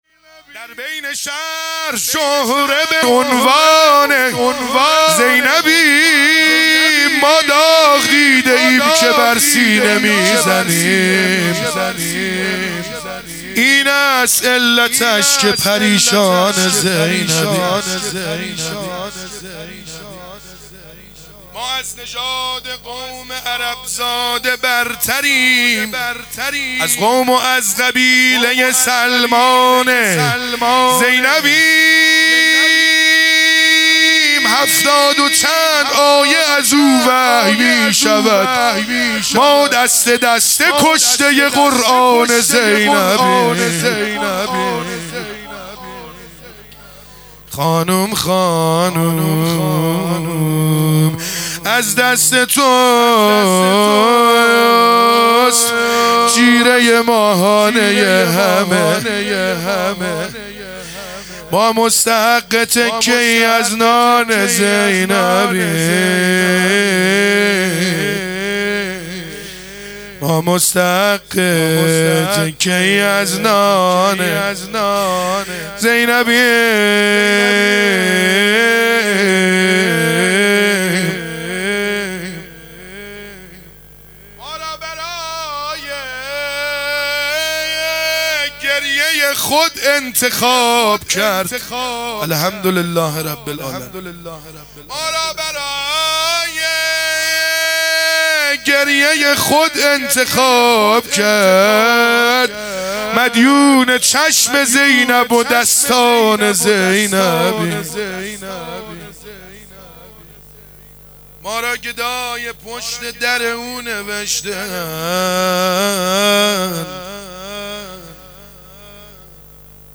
شهادت حضرت زینب (س) | 27 بهمن ماه 1400 | شعر خوانی